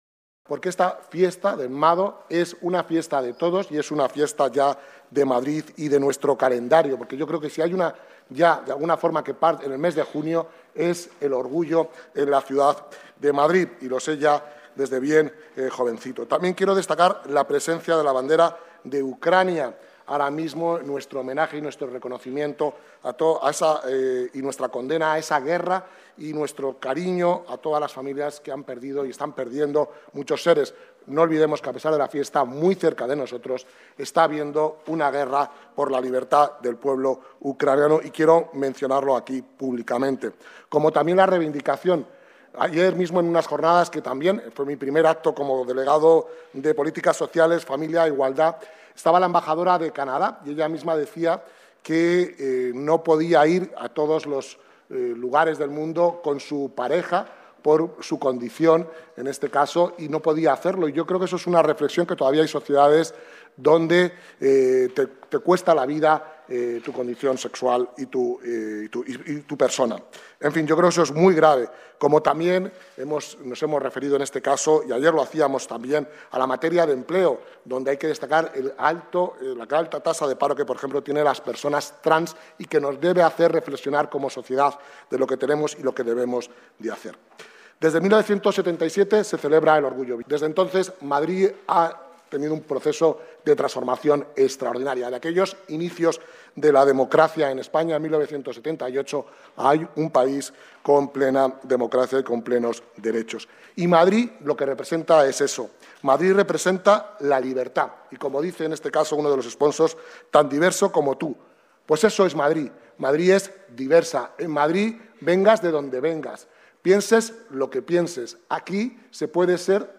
Nueva ventana:Declaraciones del delegado de Políticas Sociales, Familia e Igualdad, José Fernández